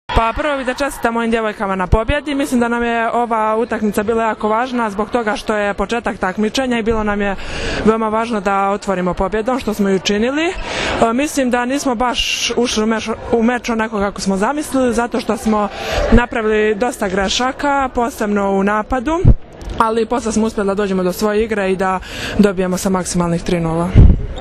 IZJAVA TIJANE BOŠKOVIĆ